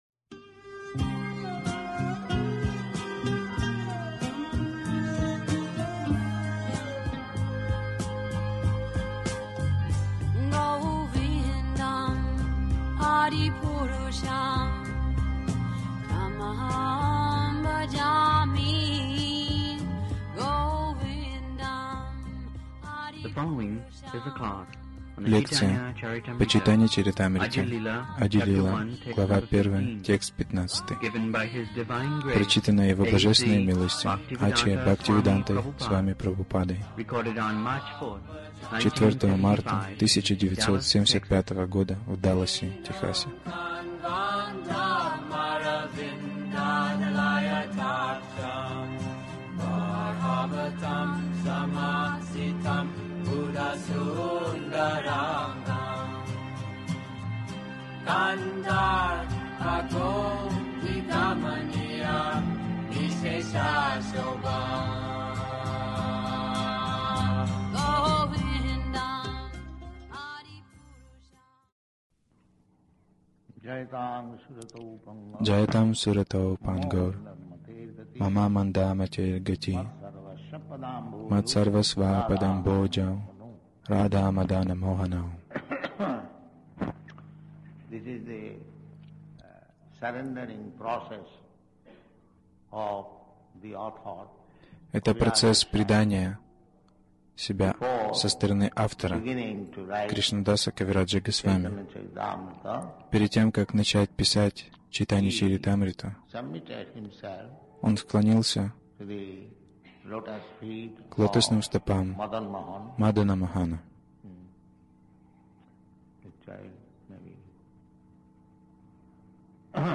Милость Прабхупады Аудиолекции и книги 04.03.1975 Чайтанья Чаритамрита | Даллас ЧЧ Ади-лила 01.015 Загрузка...